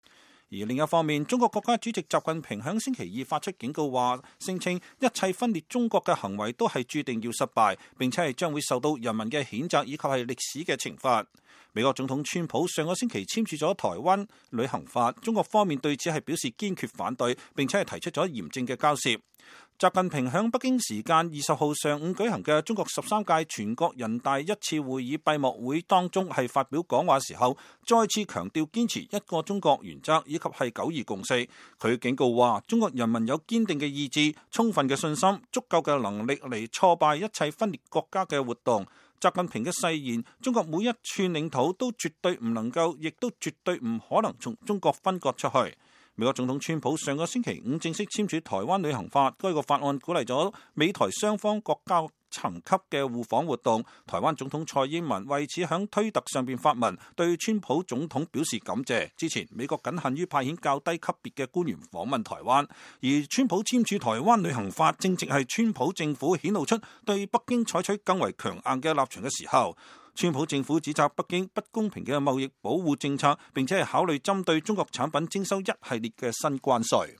中國國家主席習近平星期二在中國十三屆全國人大一次會議閉幕會中發表講話。